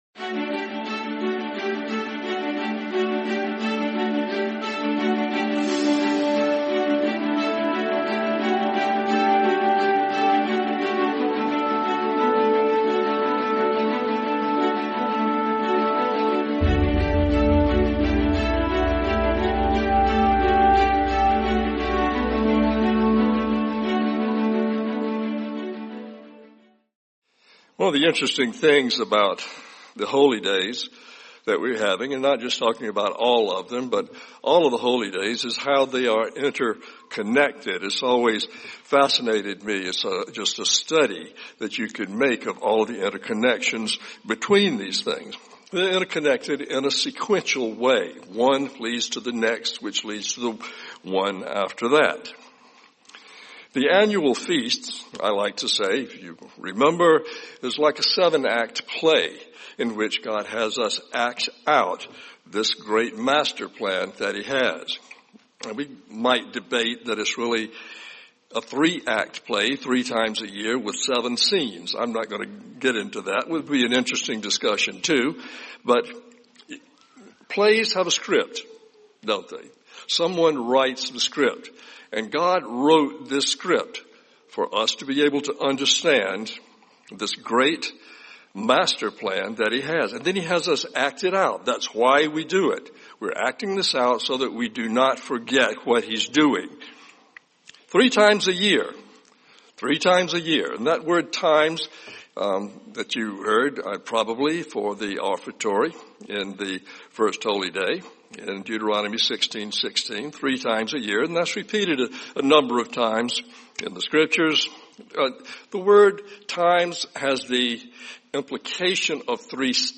Christ Our Passover and Bread of Life | Sermon | LCG Members